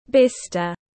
Màu bồ hóng tiếng anh gọi là bistre, phiên âm tiếng anh đọc là /´bistə/.
Bistre /´bistə/